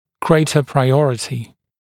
[‘greɪtə praɪ’ɔrətɪ][‘грэйтэ прай’орэти]больший приоритет